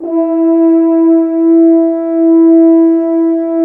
Index of /90_sSampleCDs/Roland LCDP06 Brass Sections/BRS_F.Horns 2 _/BRS_FHns Dry _